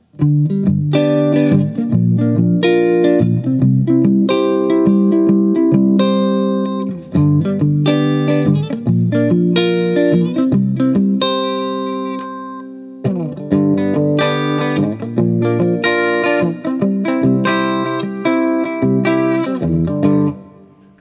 1986 X-500 Chords
Next, I set the LS-10 in front of my Axe-FX with the aforementioned Jazz patch.
I also find it interesting that the soundblock-equipped 1986 X-500 has a much tighter response when plugged in.